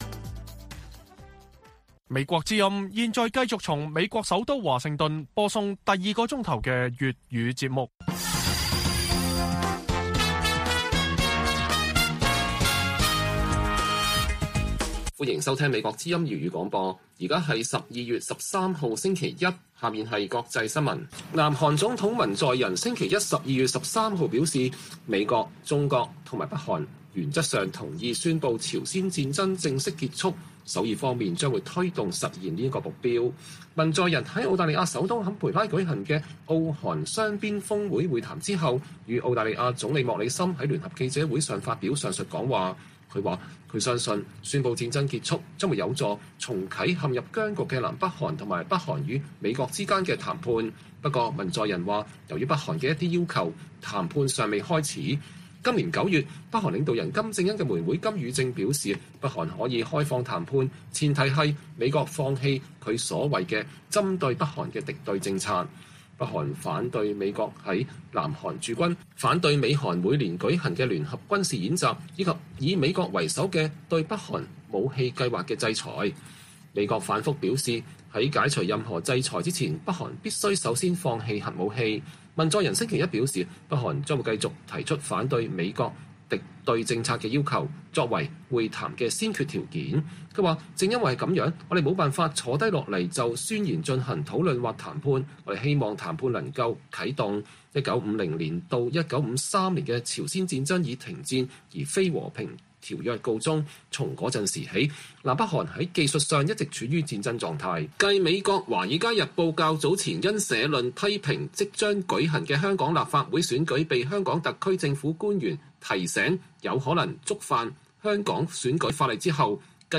粵語新聞 晚上10-11點: 文在寅稱美中與北韓原則同意正式宣告韓戰結束